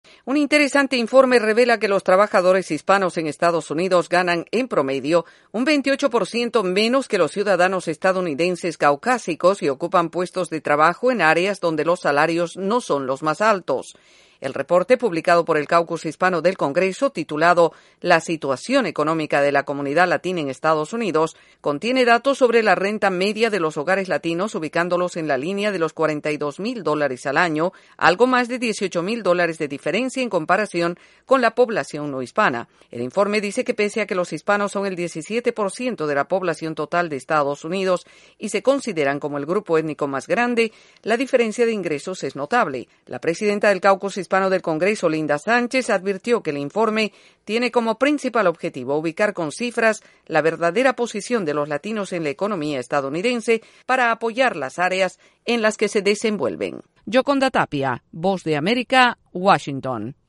Un informe revela que los hispanos tienen salarios más bajos que los blancos en Estados Unidos. Desde la Voz de América en Washington DC informa